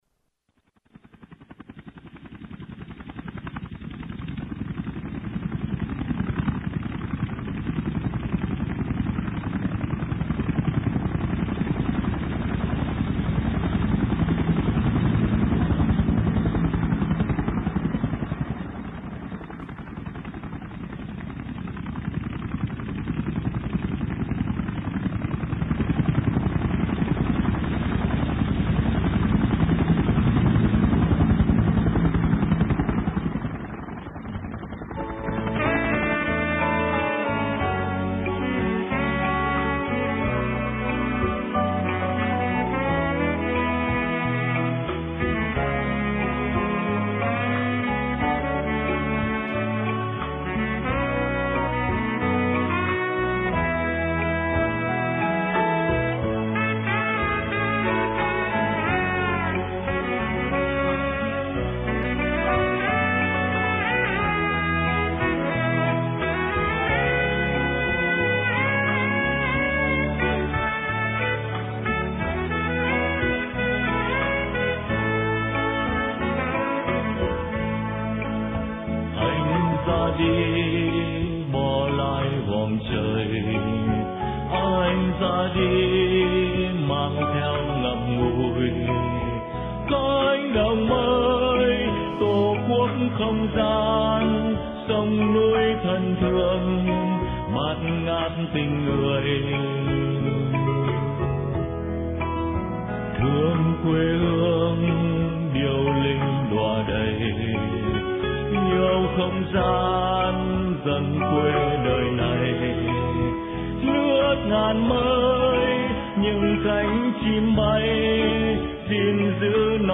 Từ Cánh Đồng Mây: Phỏng vấn